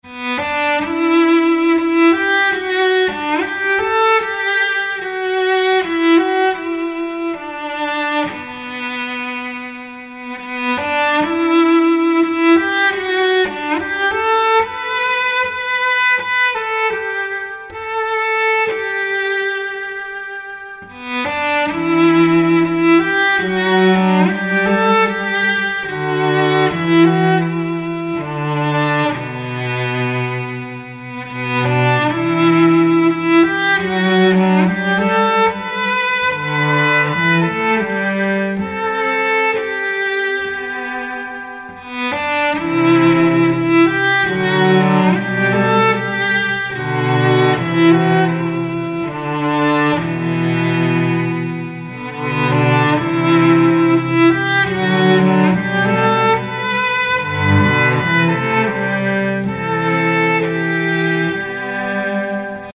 for cello & looper